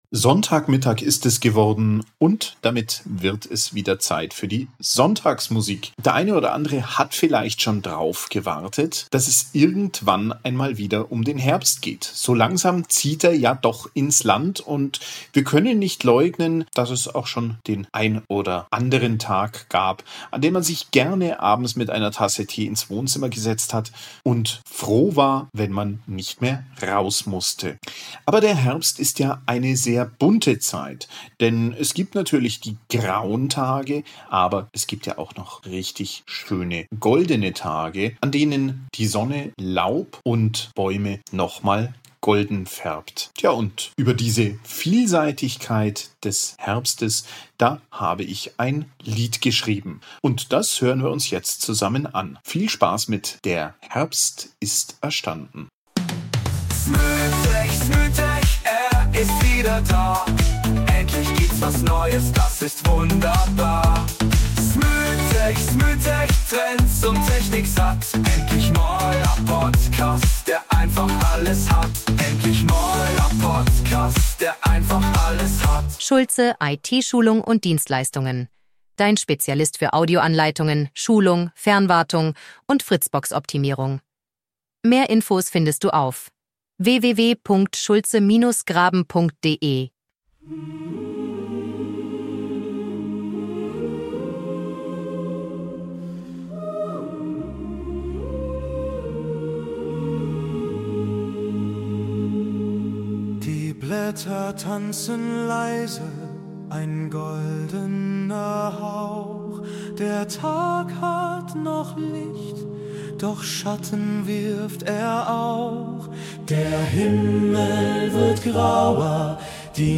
Dieses mal habe ich für die Sonntagsmusik ein Stück für einen
Chor geschrieben.